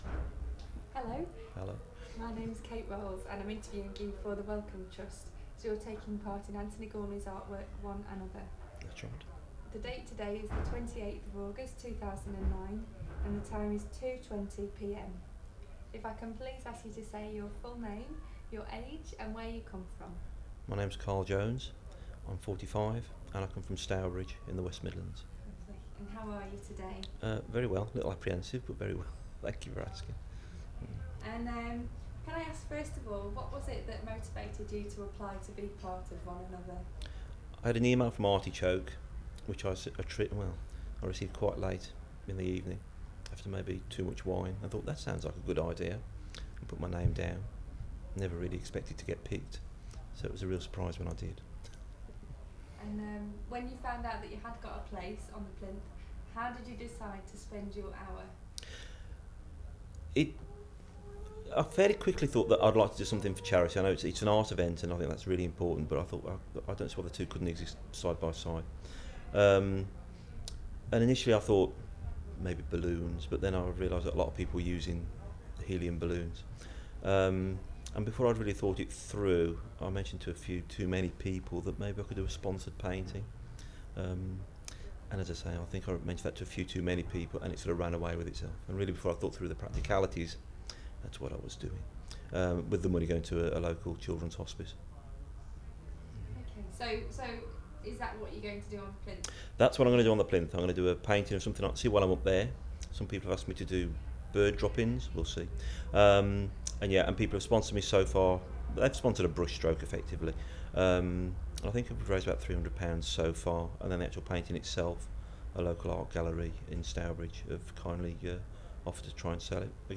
Audio file duration: 00:09:15 Format of original recording: wav 44.1 khz 16 bit ZOOM digital recorder.
These recordings are part of the One & Other interview series that has been licensed by the Wellcome Trust for public use under Creative Commons Attribution-non commercial-Share Alike 3.00 UK.